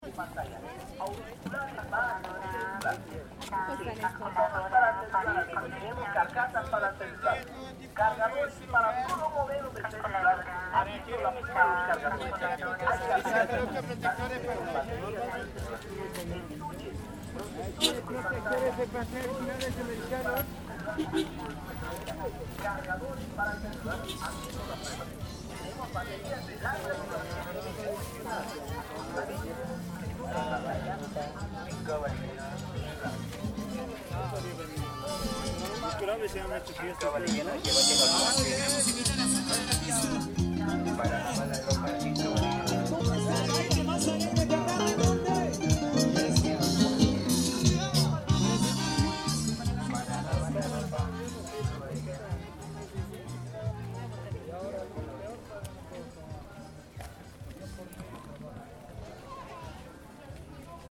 Ecoutons l’ambiance sonore de ce dimanche après-midi ensoleillé à la Feria del Avenida del 16 de Julio :